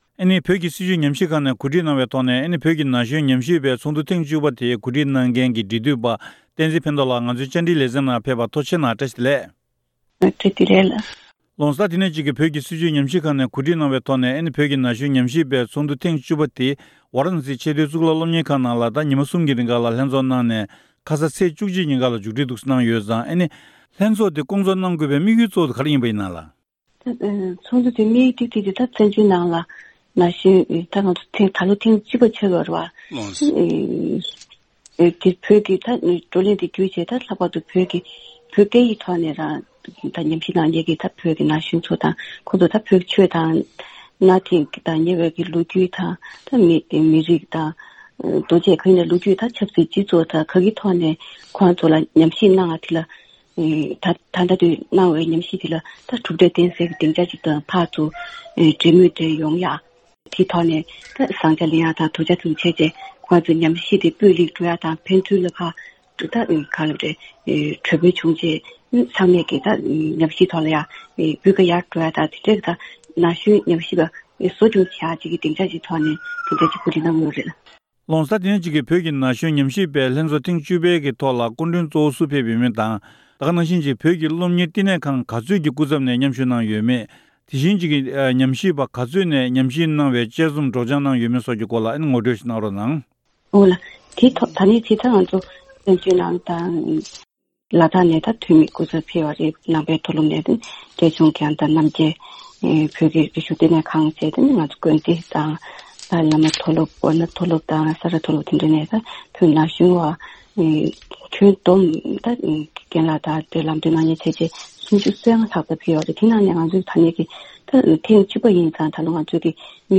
ཞལ་པར་ཐོག་བཅར་འདྲི་ཞུས་པ་ཞིག་གསན་རོགས་གནང་།